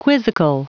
Prononciation audio / Fichier audio de QUIZZICAL en anglais
Prononciation du mot : quizzical